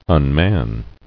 [un·man]